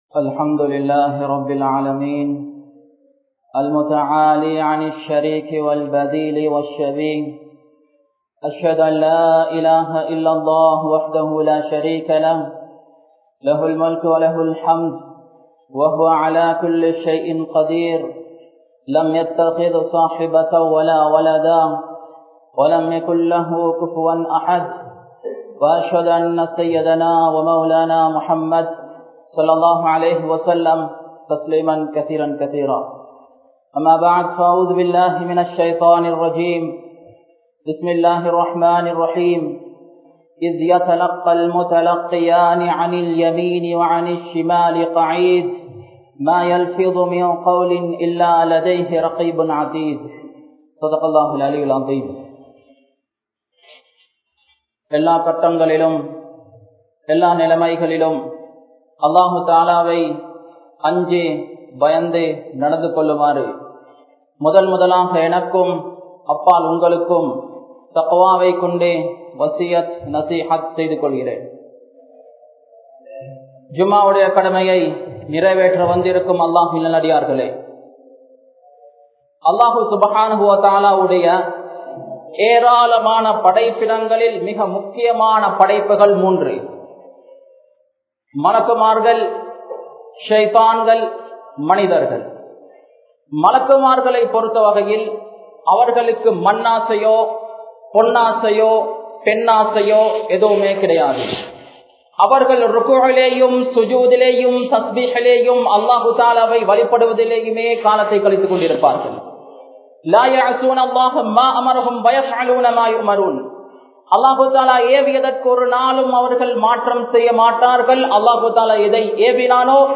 Thawba Seithavarhalin Varalaaru (தௌபா செய்தவர்களின் வரலாறு) | Audio Bayans | All Ceylon Muslim Youth Community | Addalaichenai
Jamiul Anwer Jumua Masjidh